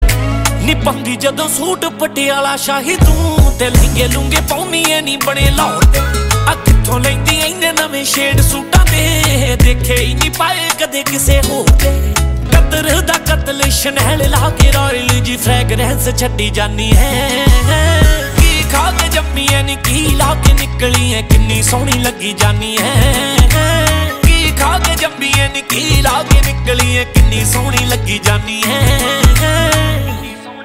Punjabi Songs